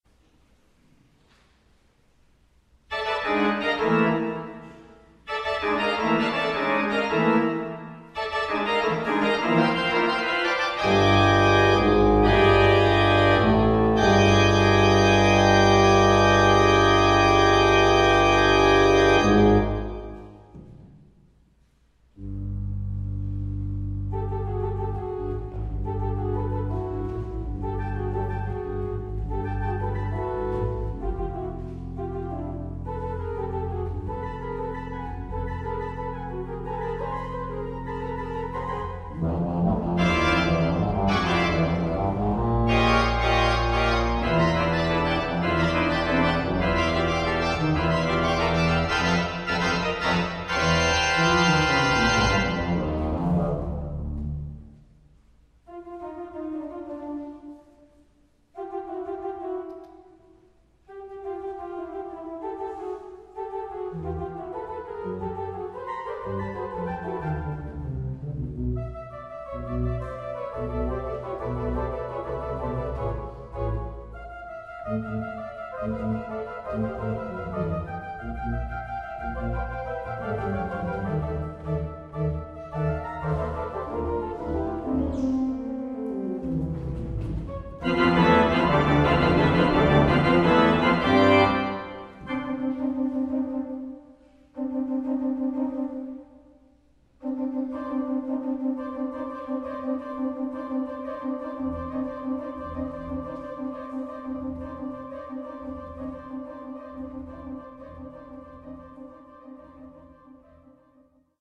Fantasie für Orgel über